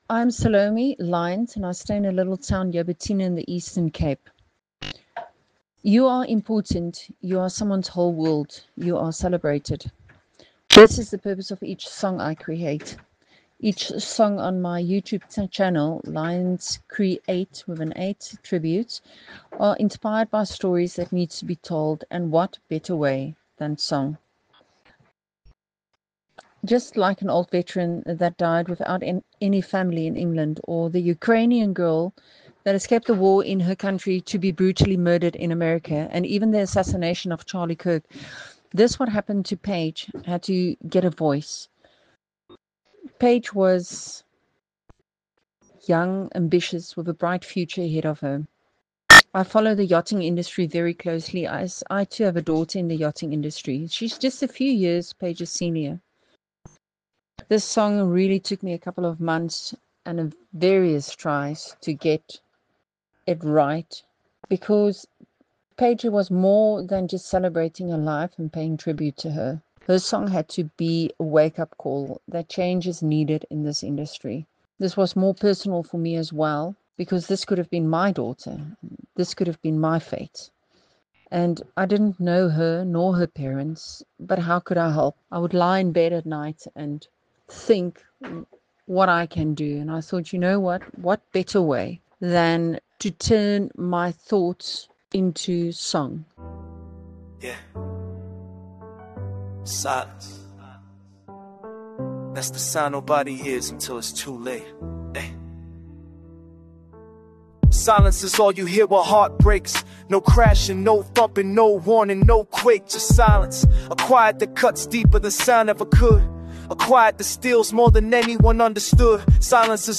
As we close off 16 Days of Activism, we reflect on her story and share a listener-written tribute song honouring her life, raising awareness, and reminding us why saying her name still matters.